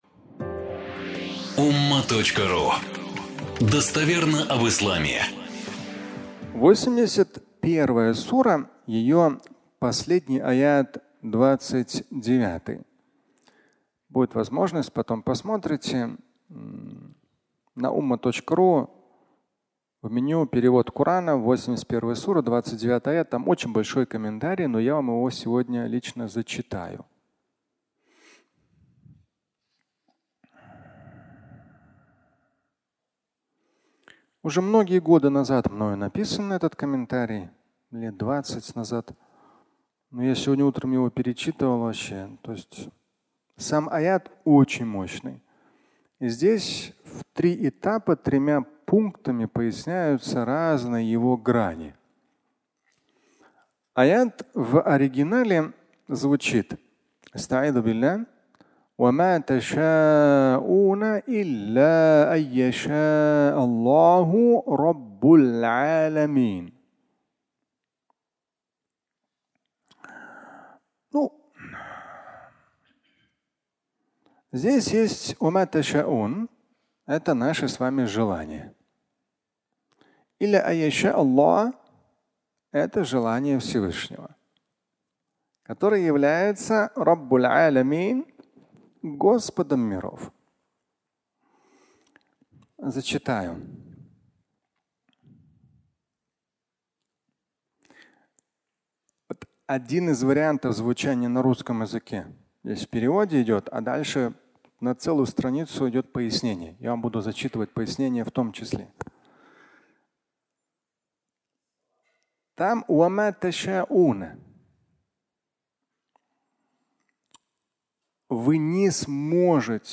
Наши желания (аудиолекция)
Фрагмент пятничной лекции